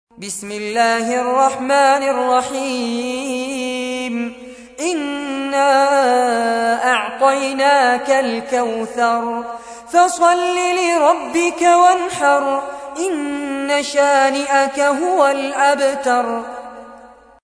تحميل : 108. سورة الكوثر / القارئ فارس عباد / القرآن الكريم / موقع يا حسين